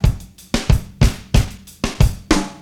Jamaica 3 93bpm.wav